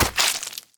claw1.ogg